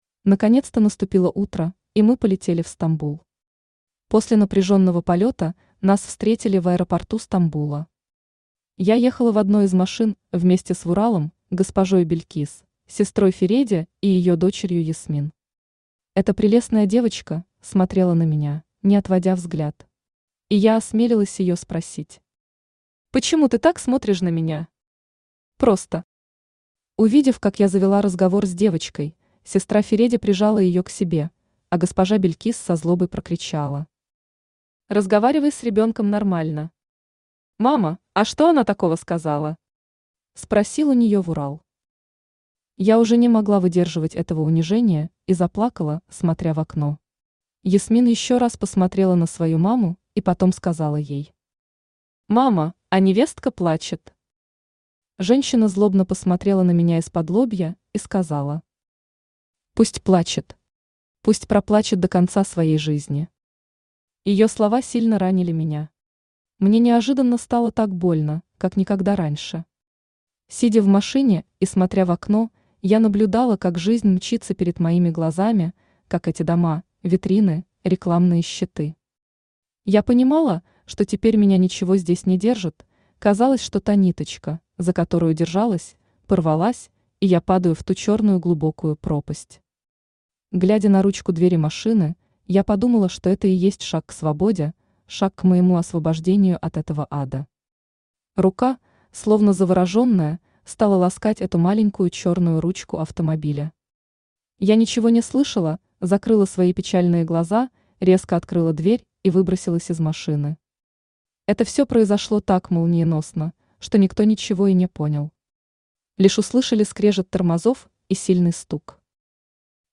Аудиокнига В доме врага. Часть 2 | Библиотека аудиокниг
Часть 2 Автор Анна Сергеевна Байрашная Читает аудиокнигу Авточтец ЛитРес.